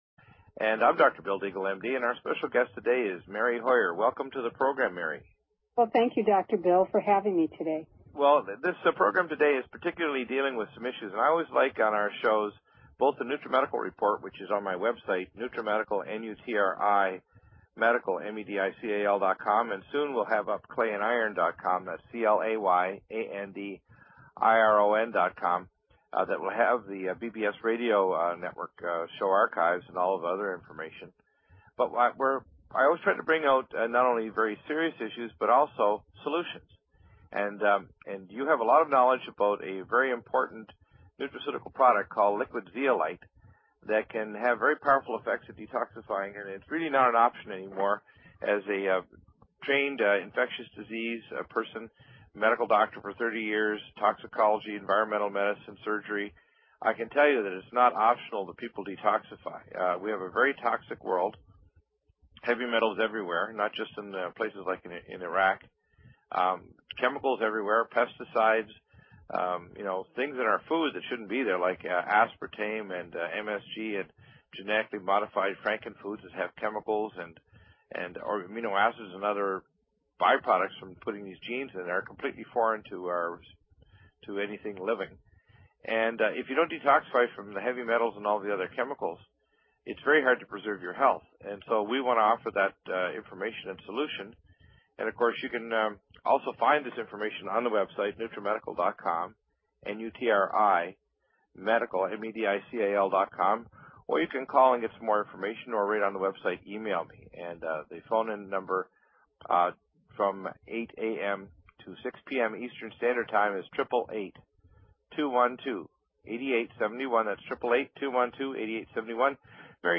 Talk Show Episode, Audio Podcast, Clay_Iron and Courtesy of BBS Radio on , show guests , about , categorized as